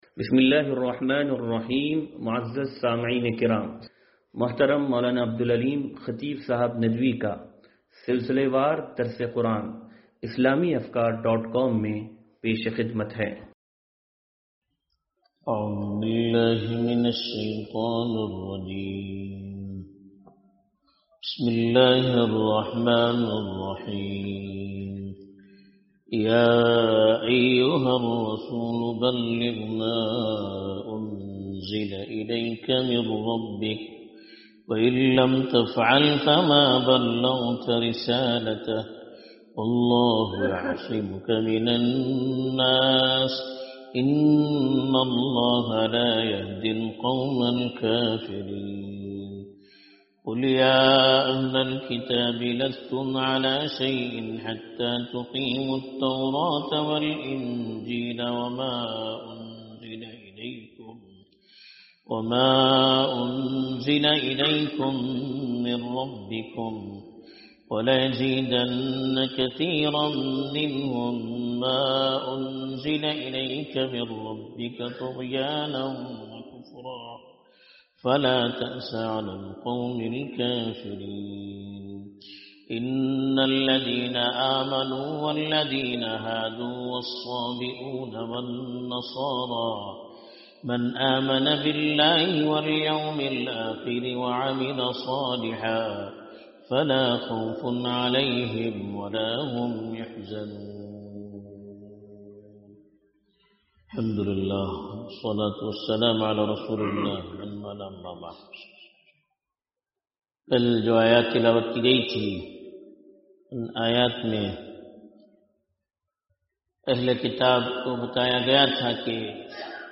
درس قرآن نمبر 0469